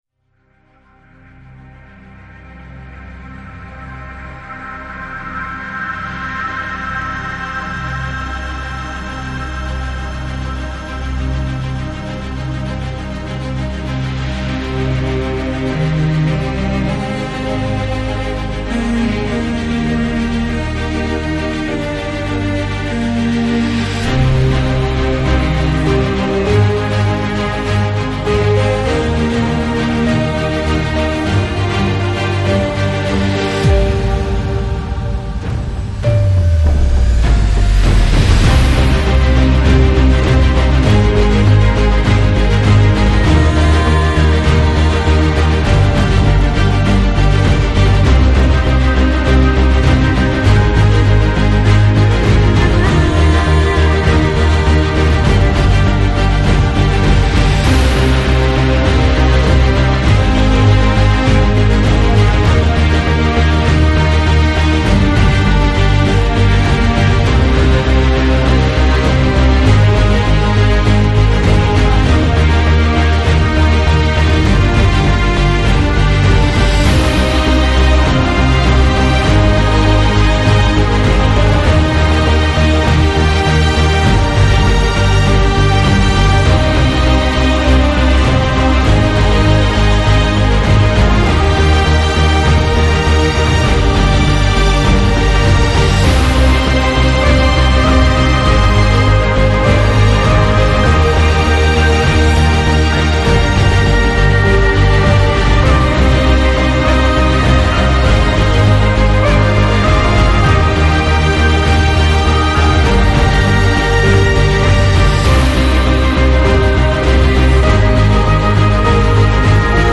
Жанр: New Age, Epic, Enigmatic